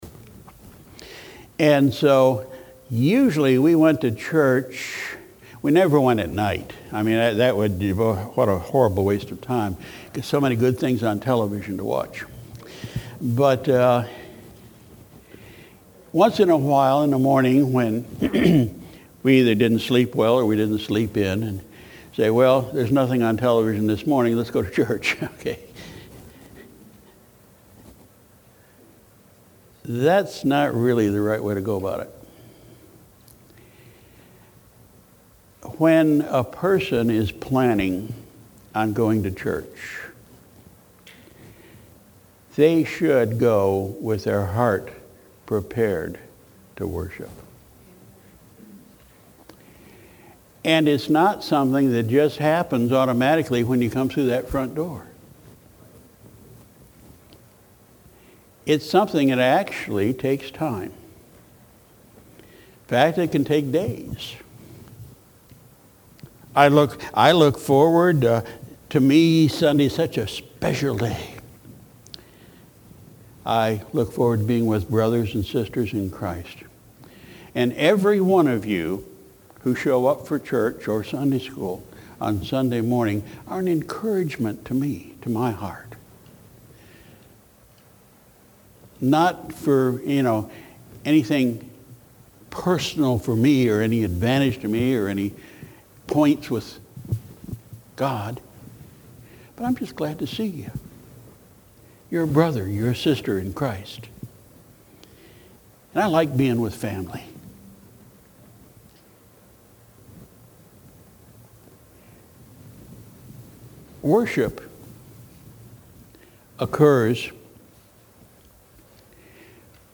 Sunday, February 25, 2018 – Morning Service – Coastal Shores Baptist Church